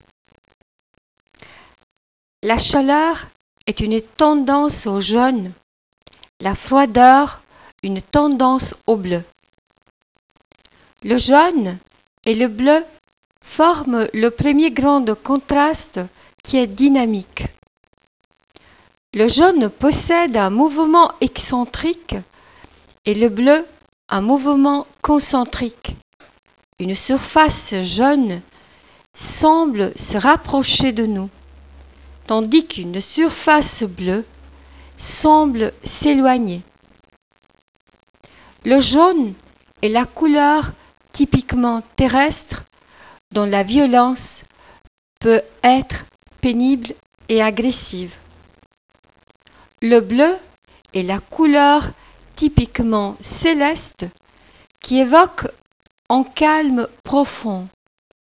n° 1 lecture articulée